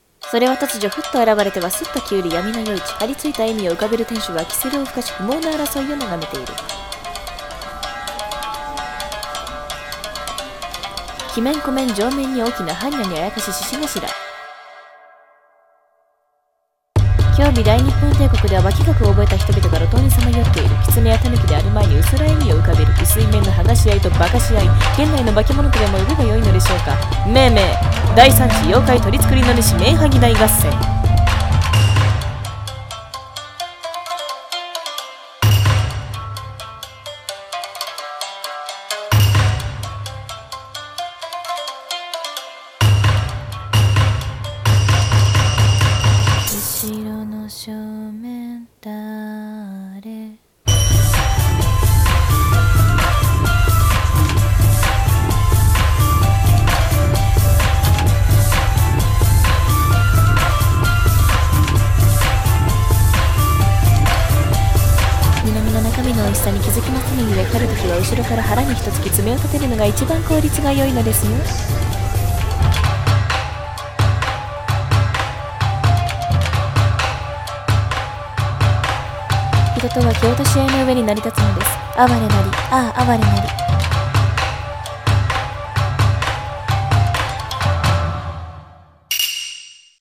CM風声劇「第参次面剥合戦」